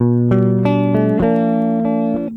gtr_04.wav